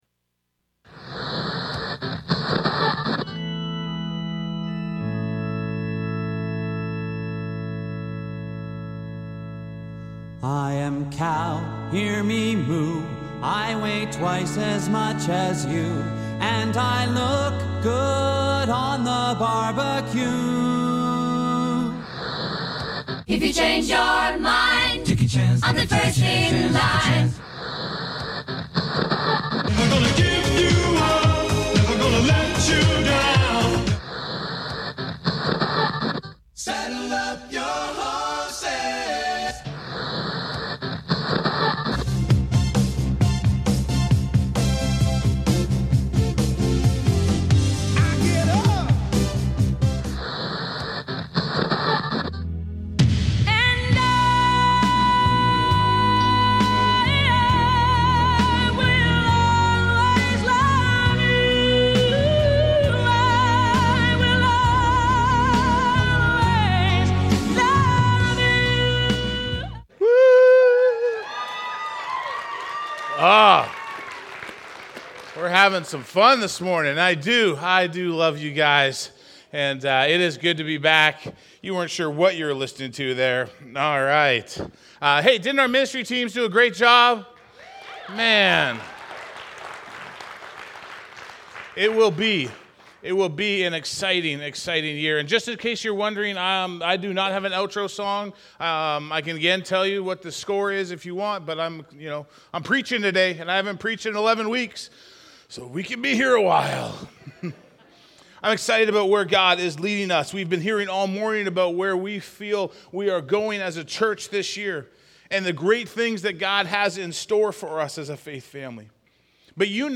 Vision Sunday 2024 Sermon